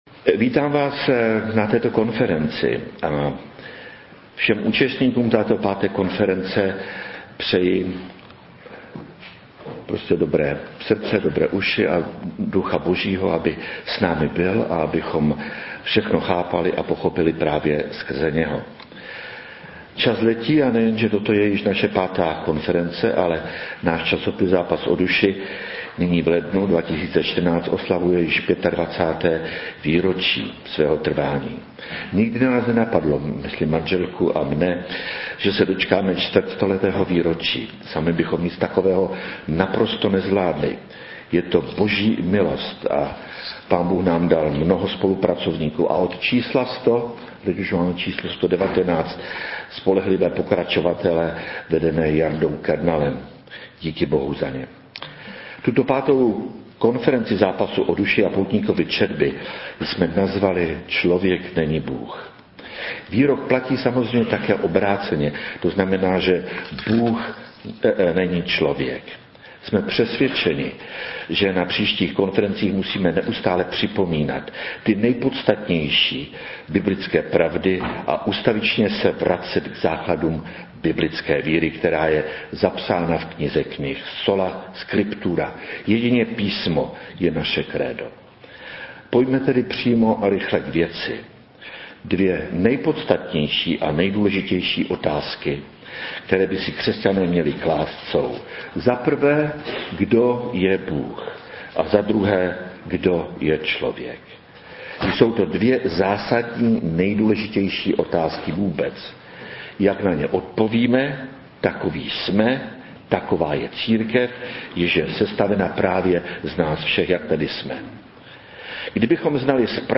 V úvodu do okruhu otázek a odpovědí, kdo je Bůh, a kdo je člověk, uvedeme důvod, proč jsme toto téma zvolili pro naši konferenci. Věříme, že všechny problémy v církvi, i v osobním životě, mají kořen v nepochopení těchto dvou základních, a ve všem zásadních, otázkách.